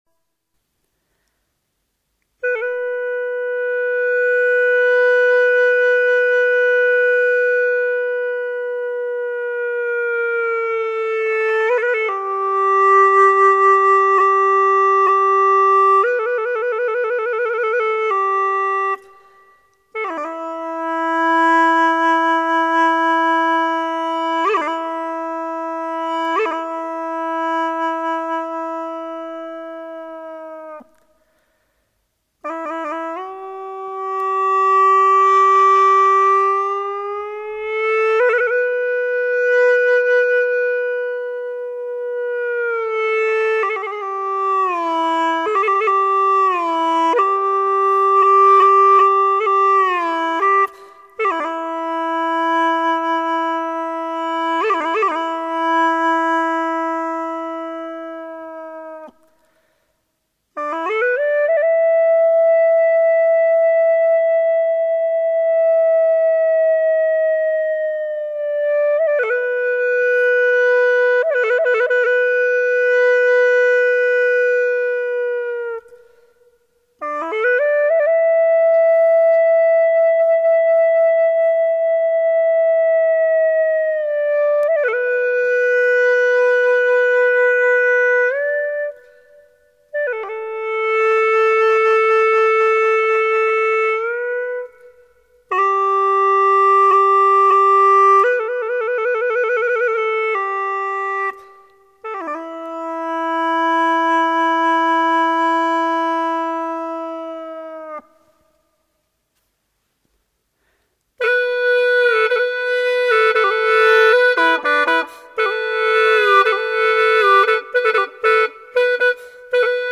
调式 : G